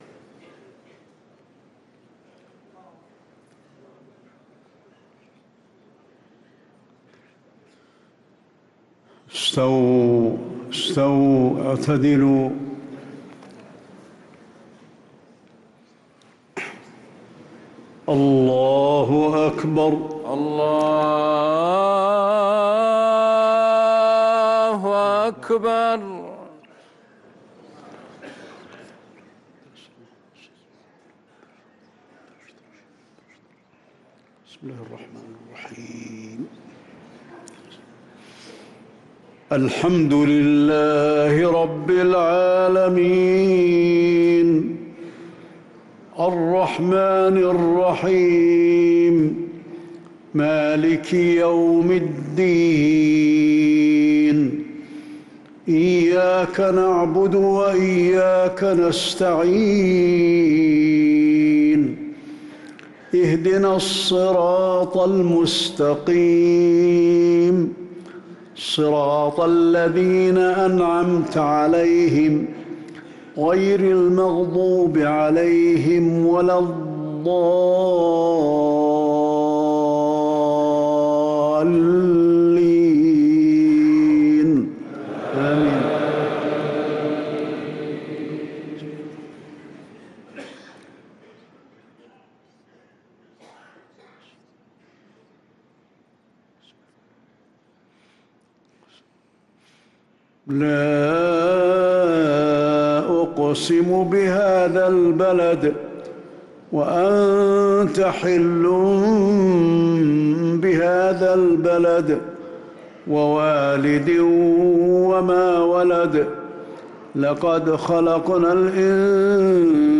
صلاة العشاء للقارئ علي الحذيفي 6 شعبان 1444 هـ
تِلَاوَات الْحَرَمَيْن .